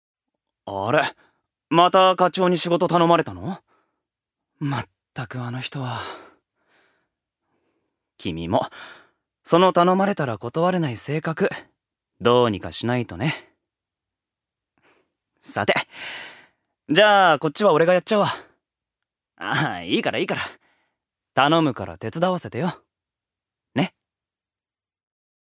所属：男性タレント
音声サンプル
セリフ１